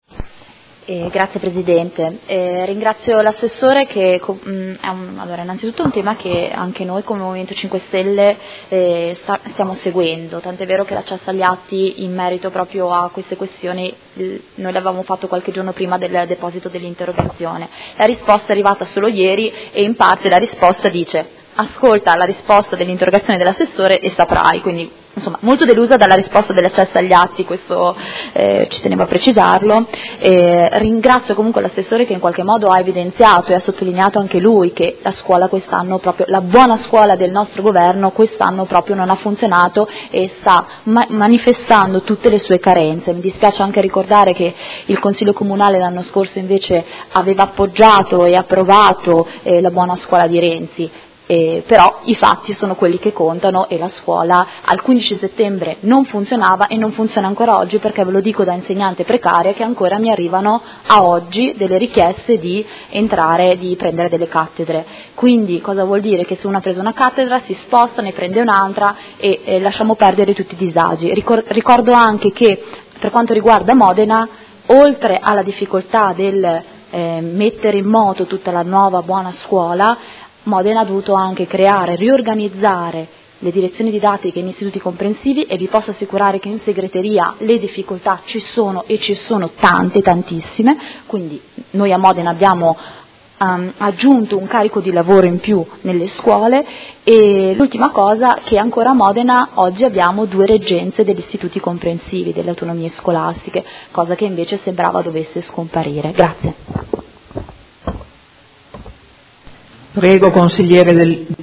Seduta del 20/10/2016. Dibattito su interrogazione del Consigliere Rocco (FaS-SI) avente per oggetto: Inizio anno scolastico con gravi difficoltà organizzative